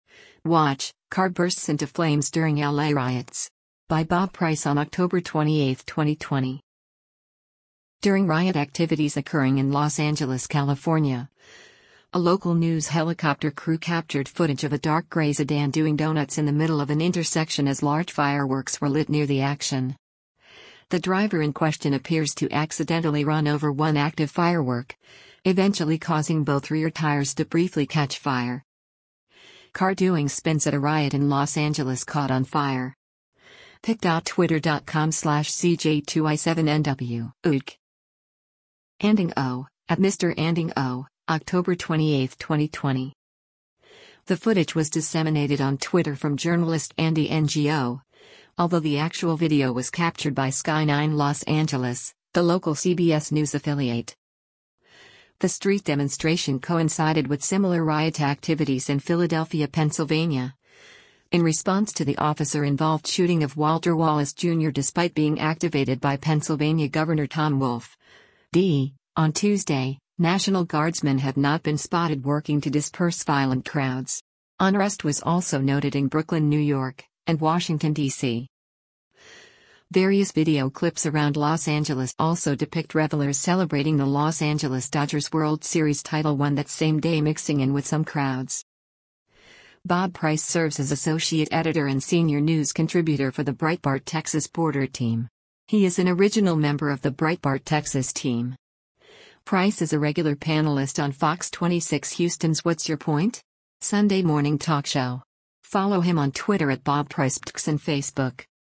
During riot activities occurring in Los Angeles, California, a local news helicopter crew captured footage of a dark grey sedan doing donuts in the middle of an intersection as large fireworks were lit near the action. The driver in question appears to accidentally run over one active firework, eventually causing both rear tires to briefly catch fire.